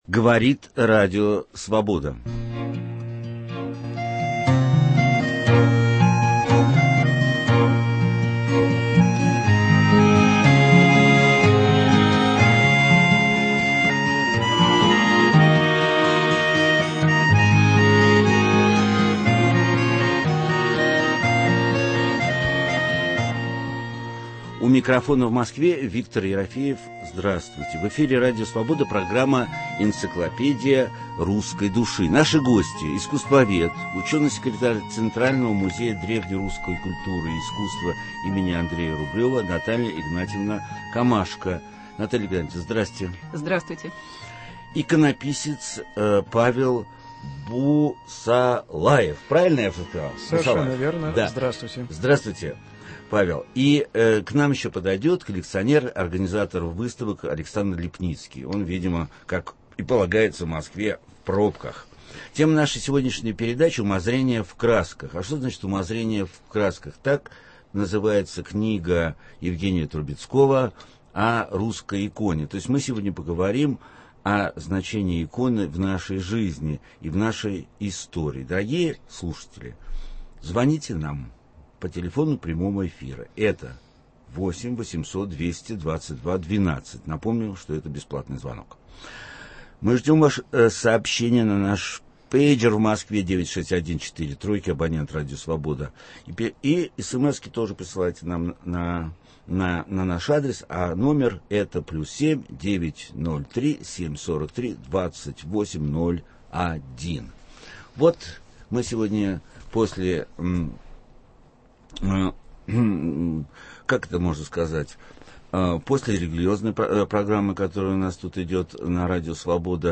В прямом эфире мы поговорим на тему "Русские иконы".